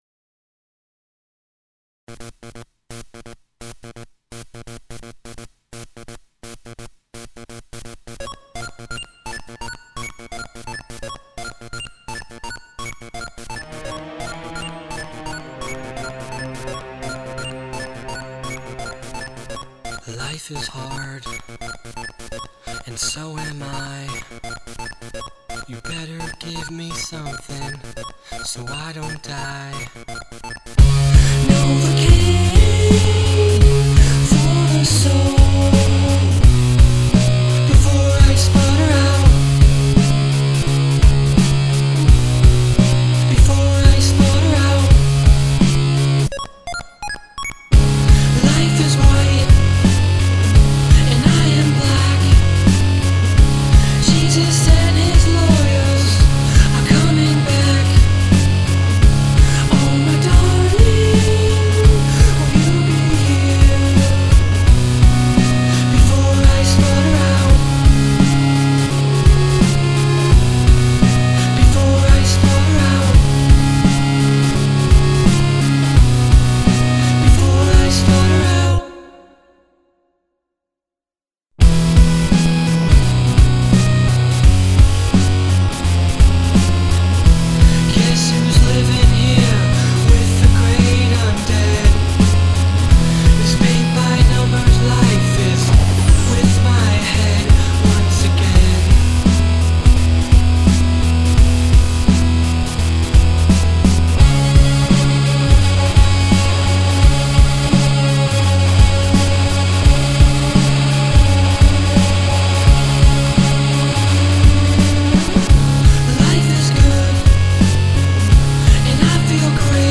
I used midines, guitars, and some lil string things...